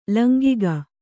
♪ langiga